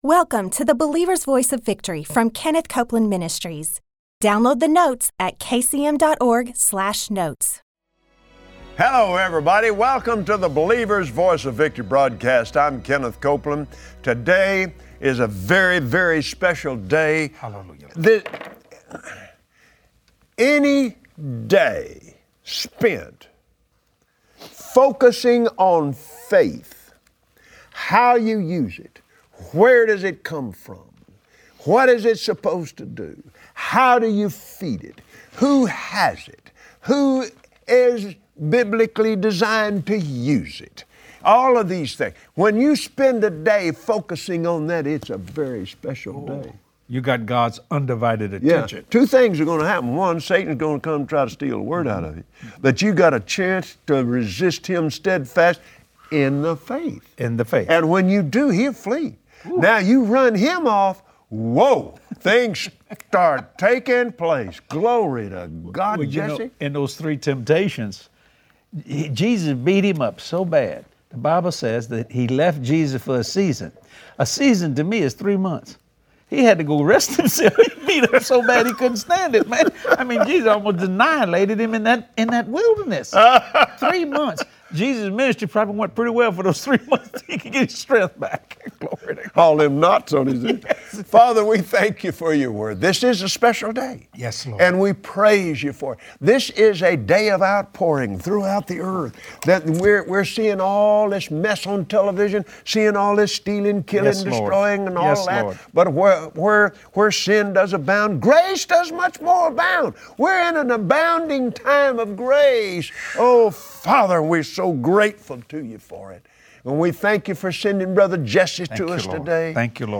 The Father has good things in store as you follow Him. Join Kenneth Copeland and Jesse Duplantis for today’s Bible lesson, on the Believer’s Voice of Victory.